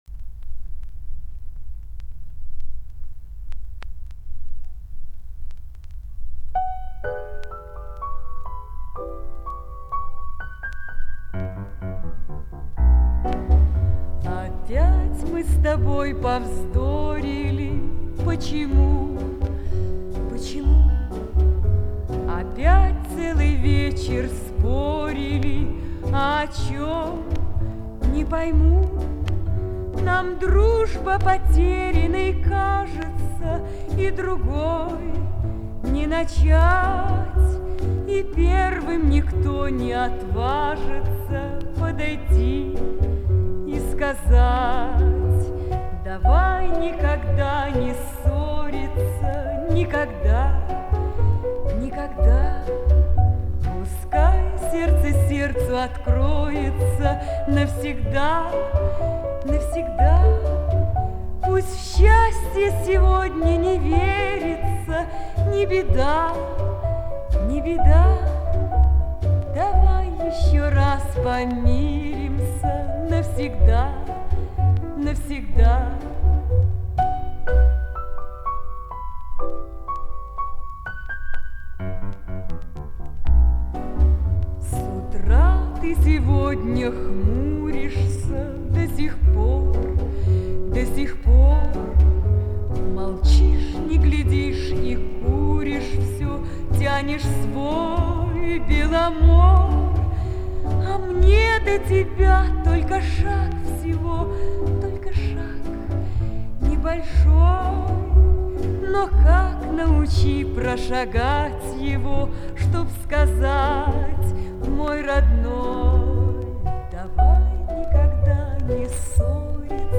В Си миноре.